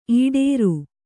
♪ īḍēru